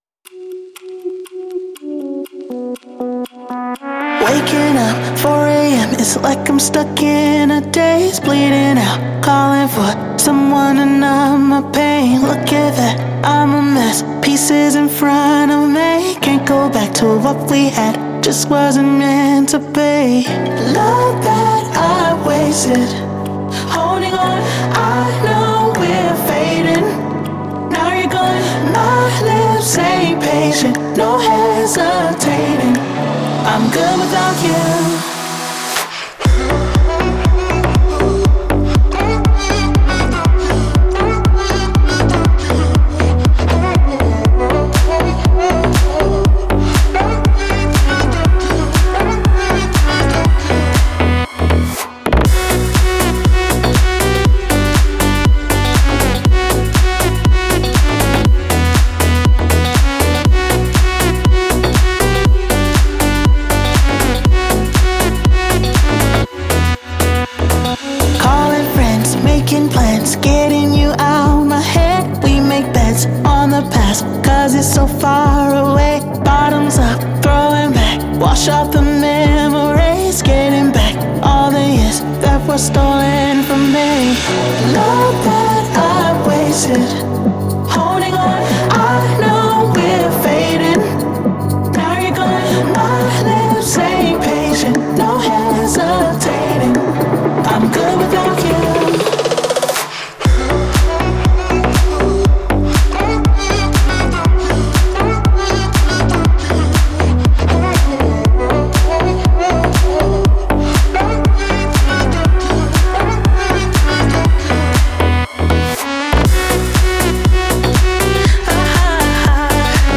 это мелодичная поп-песня